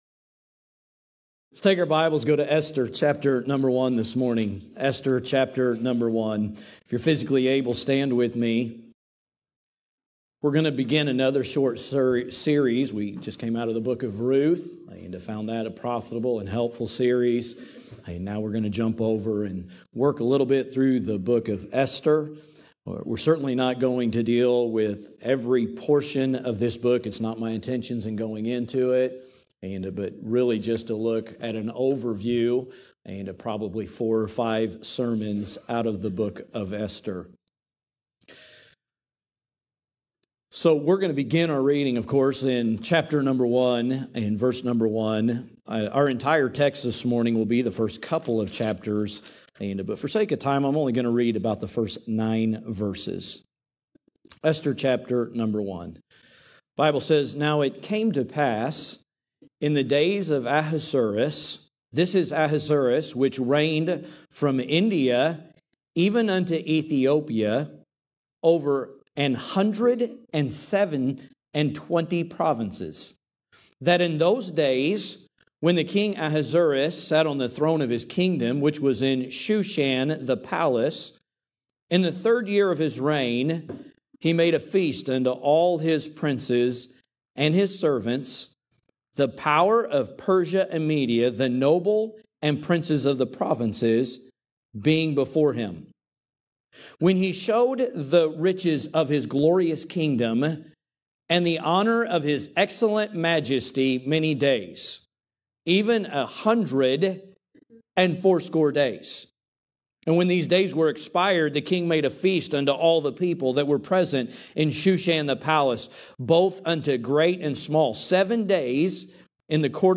A message from the series "Esther."